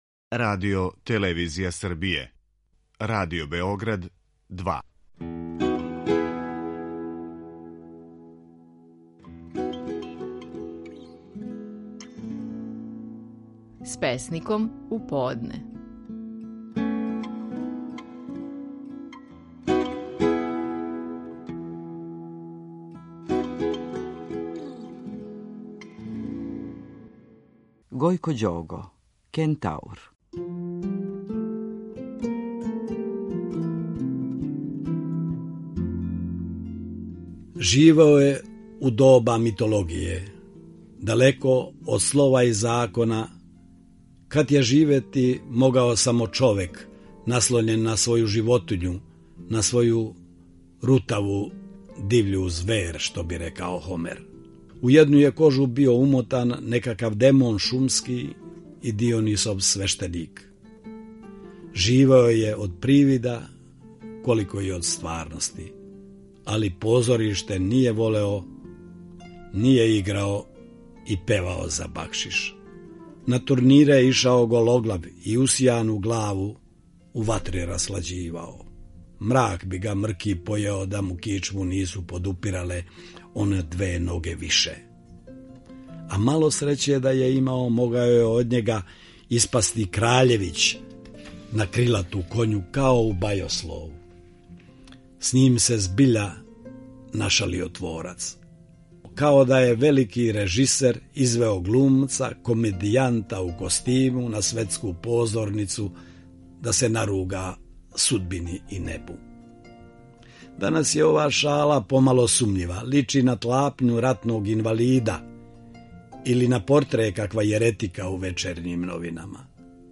Стихови наших најпознатијих песника, у интерпретацији аутора.
„Кентаур" - назив је песме коју казује Гојко Ђого.